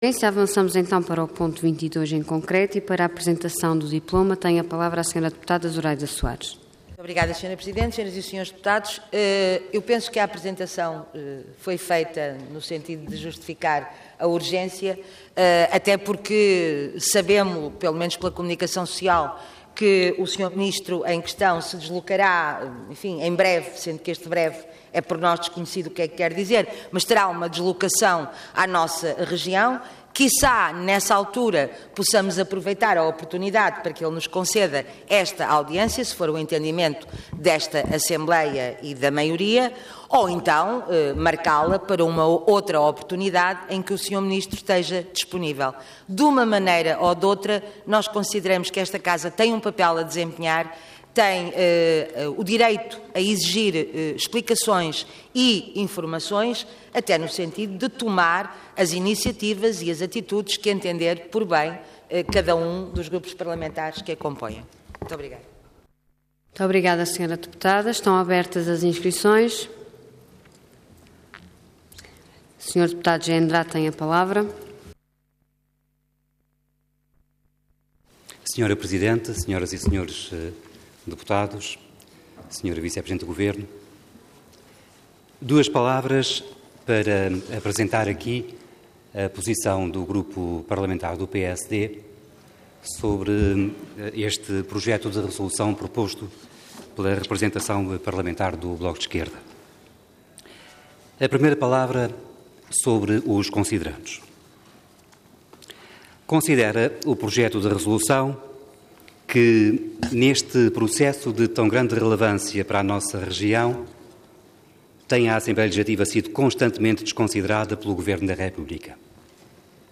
Intervenção Projeto de Resolução Orador Zuraida Soares Cargo Deputada Entidade BE